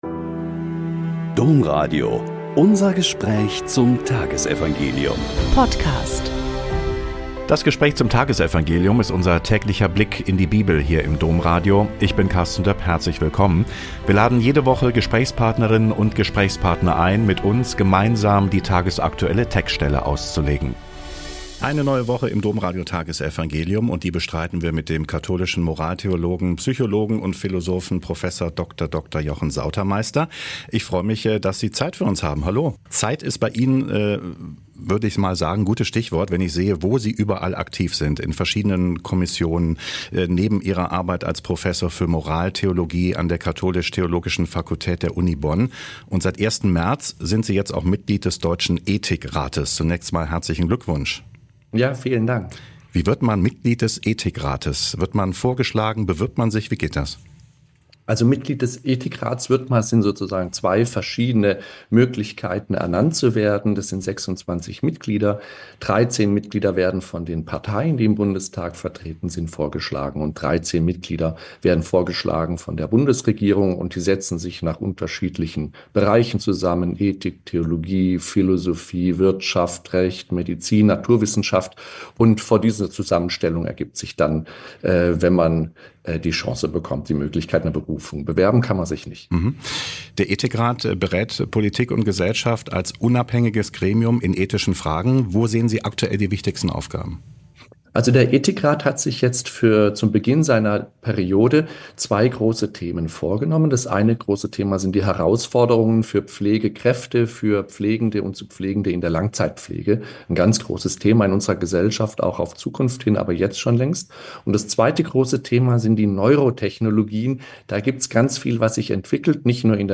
Kapitelsmesse am Hochfest Verkündigung des Herrn - 25.03.2025